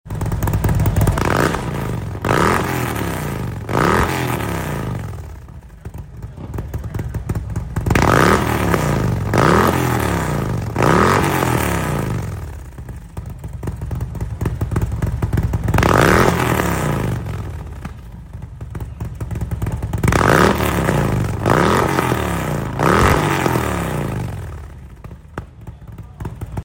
DEMO SOUND